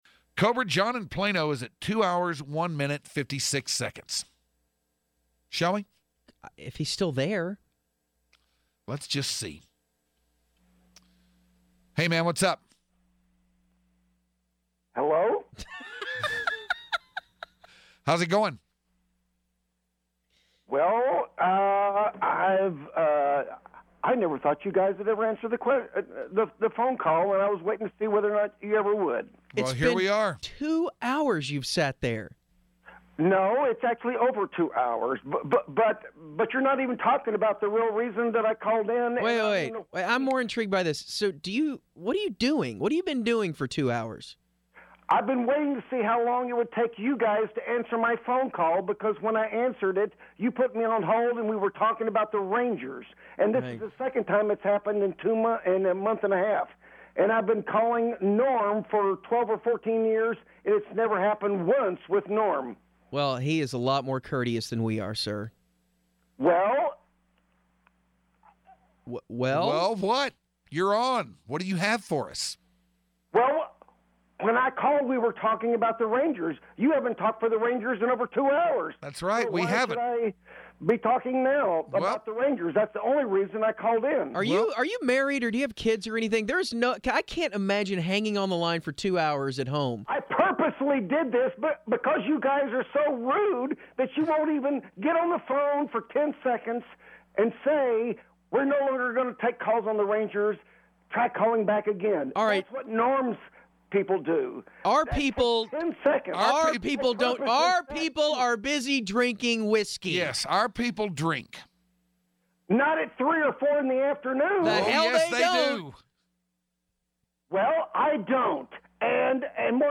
Great radio.